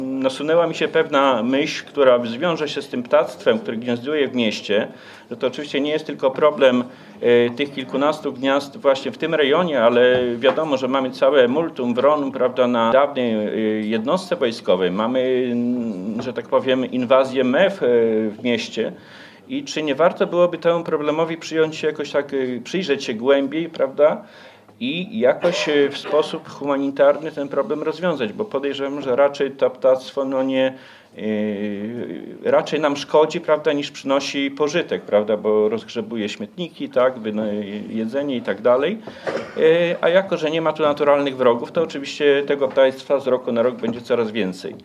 Na problemy z ptactwem zwrócił uwagę na ostatniej sesji Rady Miejskiej Andrzej Łuczaj, radny Prawa i Sprawiedliwości. Podczas interpelacji przytoczył wnioski mieszkańców, którzy narzekają na wrony gniazdujące w bloku przy ulicy Dwernickiego.
Andrzej-Łuczaj-radny-Prawa-i-Sprawiedliwości.mp3